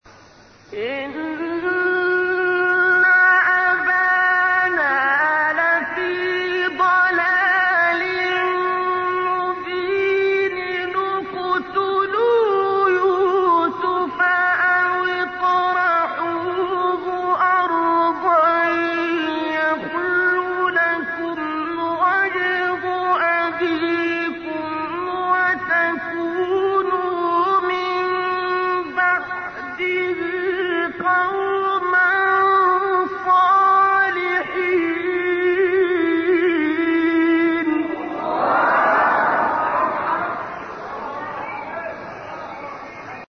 گروه شبکه اجتماعی: مقاطع صوتی از تلاوت‌های قاریان برجسته مصری را می‌شنوید.
مقطعی از عبدالباسط در مقام صبا